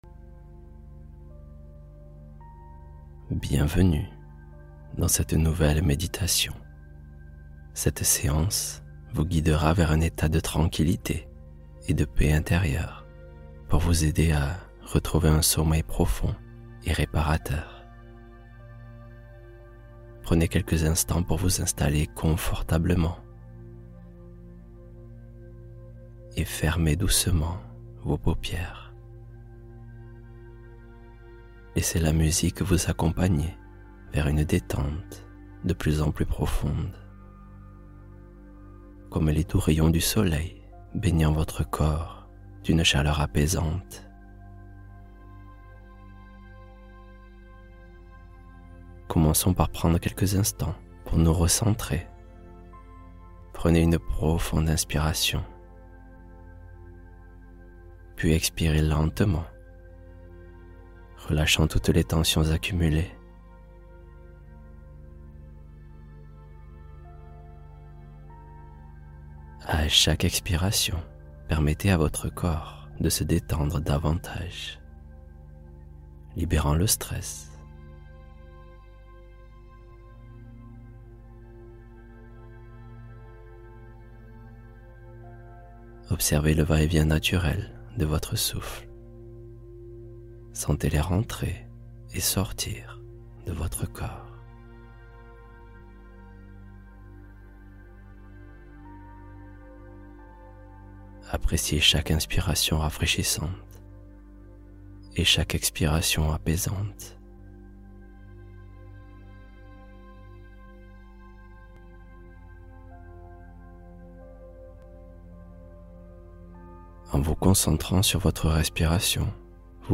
Reprogrammer le subconscient — Méditation guidée pour soutenir la confiance